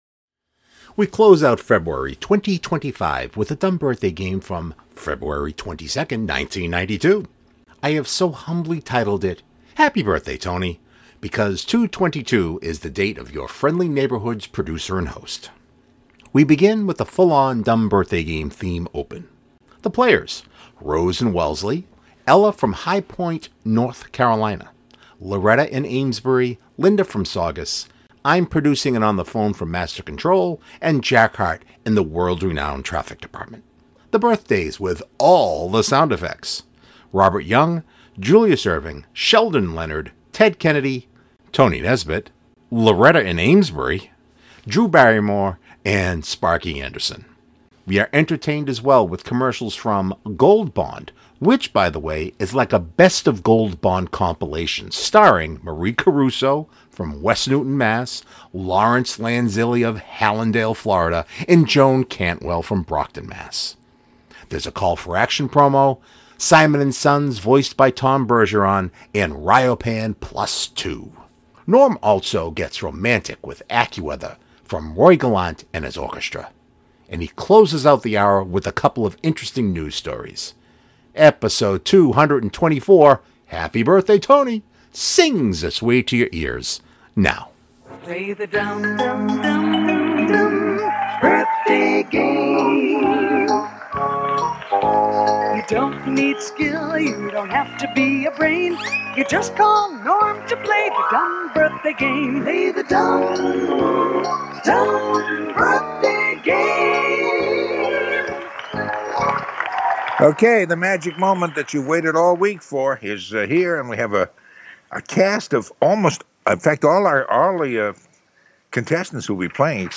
We begin with a full on DBG theme open.